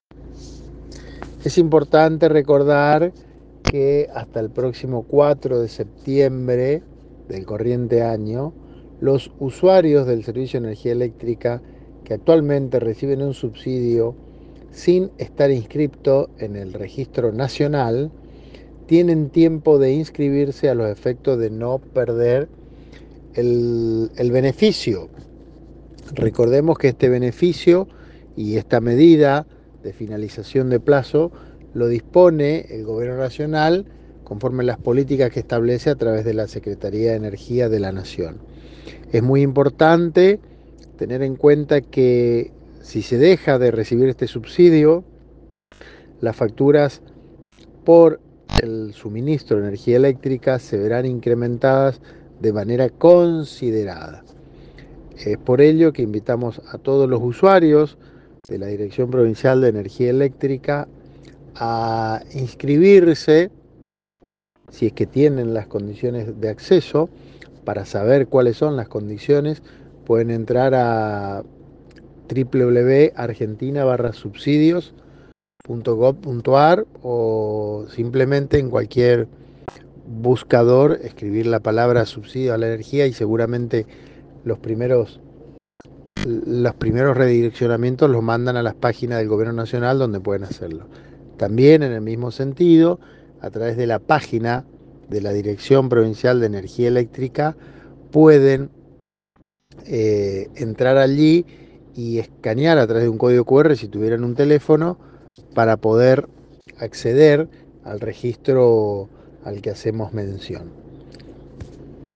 Cómo hacer la gestión para inscribirse, dónde solicitar y otros detalles los brindó el funcionario esta mañana en PONELE H, el programa de la primera mañana de FM Fénix, de Paso de los Libres.